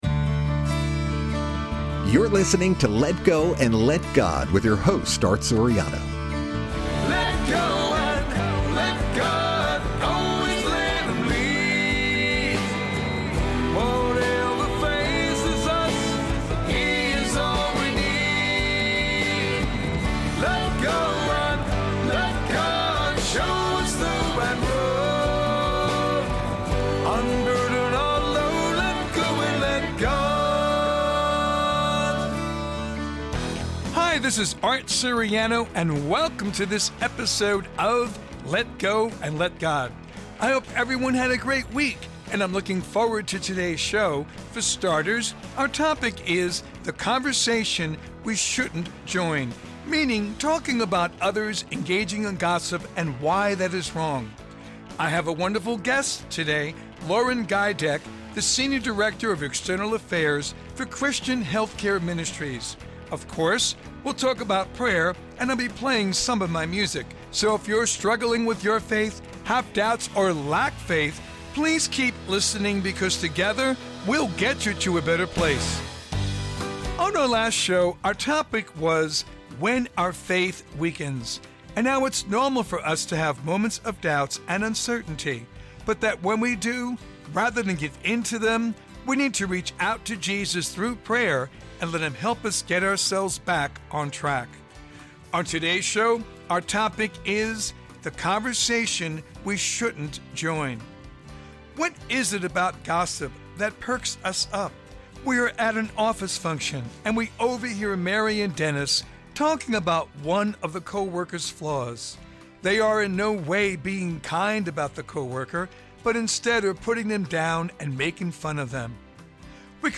This is a weekly show geared to helping those struggling with their faith. We feature different topics, from time to time guests, and Christian Contemporary Music.